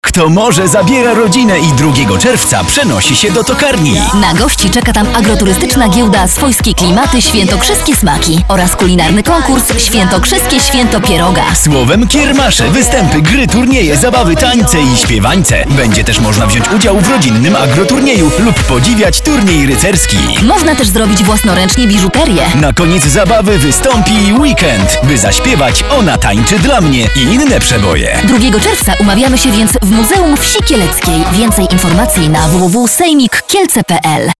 zapowiedź